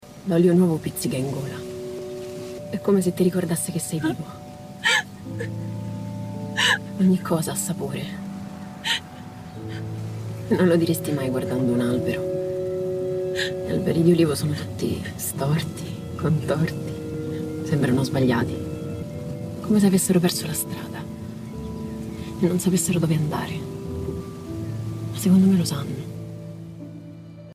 La pellicola, prodotta da Kairos Cooperativa Sociale, affronta il tema della violenza psicologica sulle donne. Ascoltiamone un estratto.
sonoro_gocce.mp3